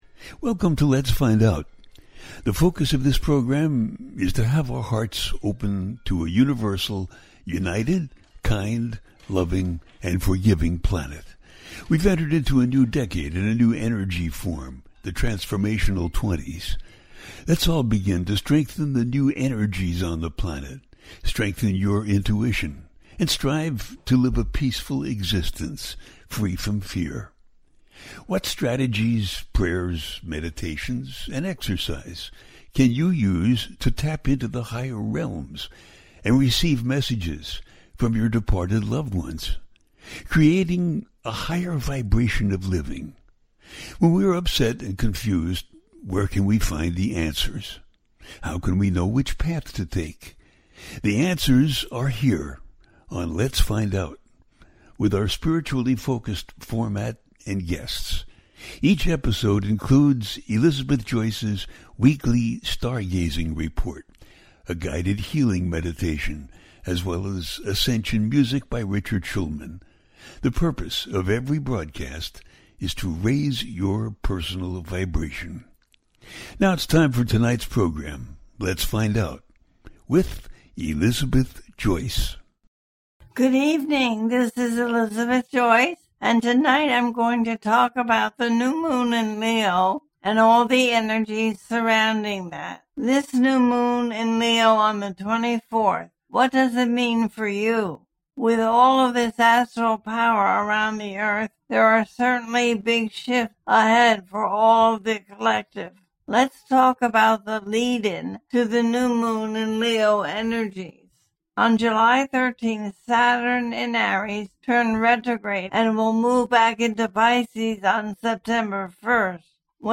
The Powerful New Moon In Leo and Beyond - A teaching show
The listener can call in to ask a question on the air.
Each show ends with a guided meditation.